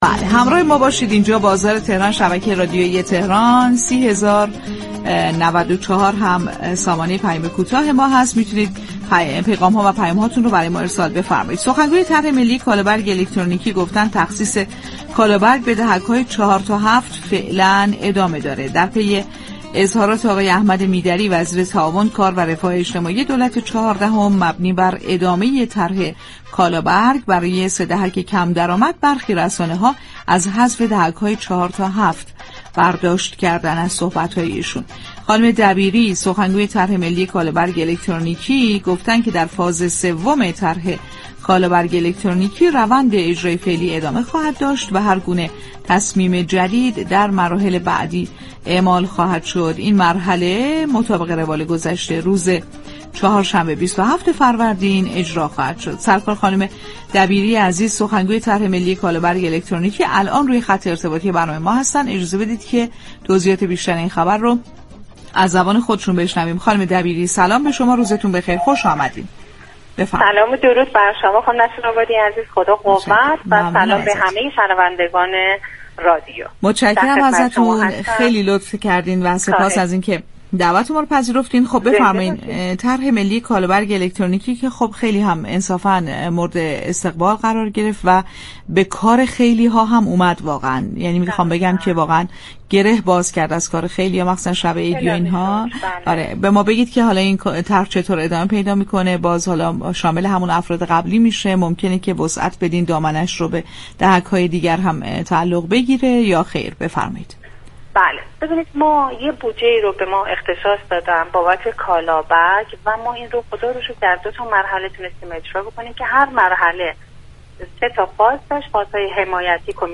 در گفت و گو با برنامه «بازار تهران»